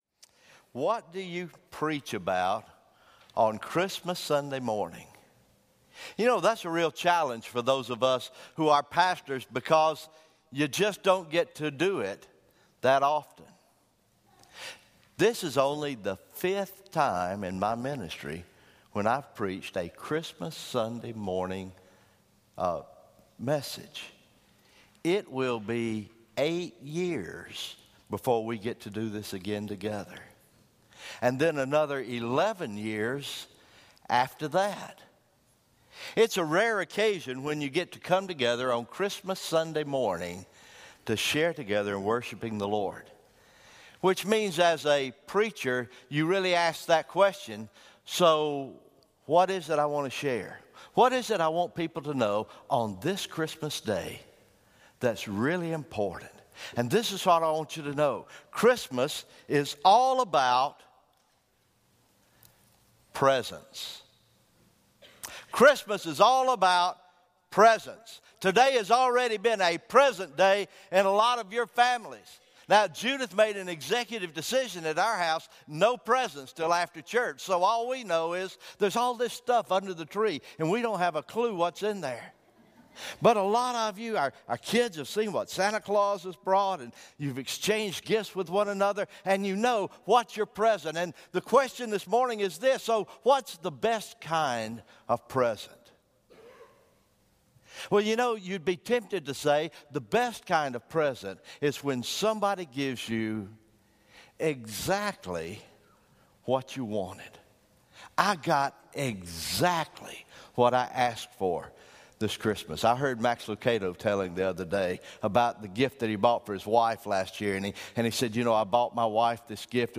December 25, 2016 Christmas Morning Worship